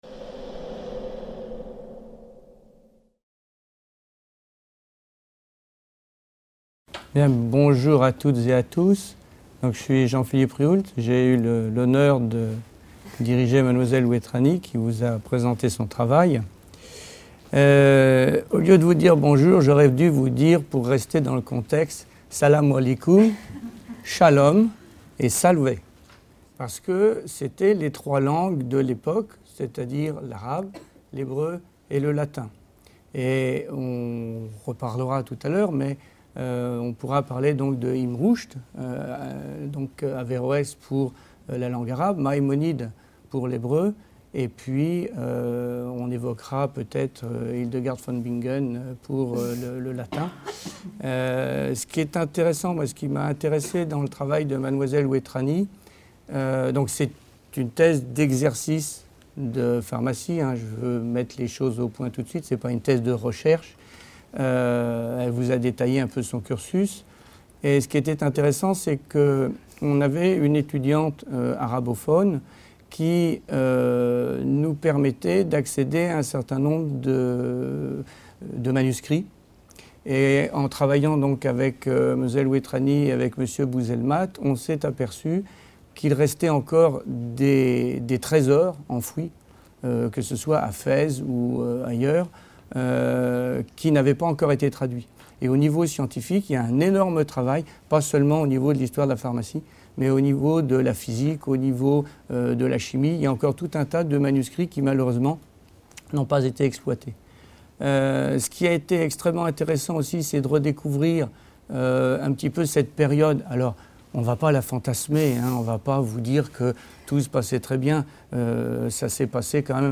Les conférences santé de la BU
Echange avec le public